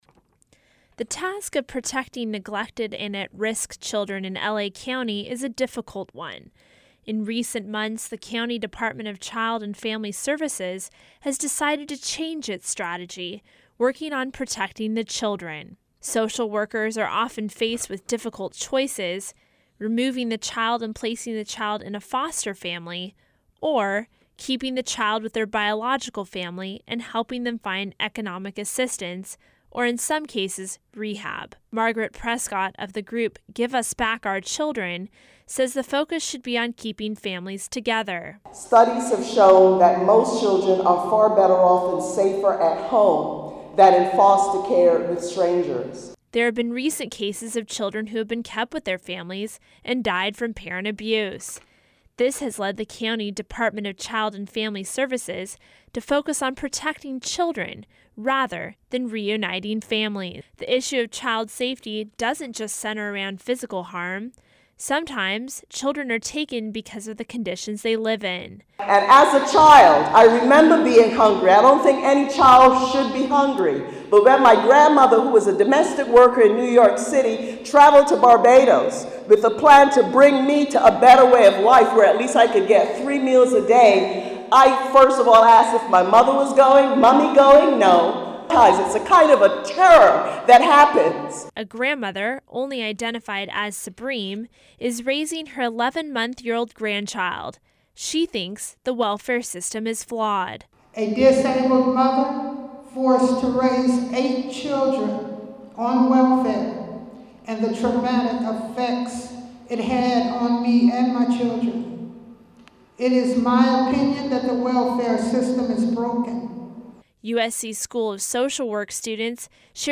Activists and social workers rallied at an event held in the United University Church to keep families together and children out of foster homes.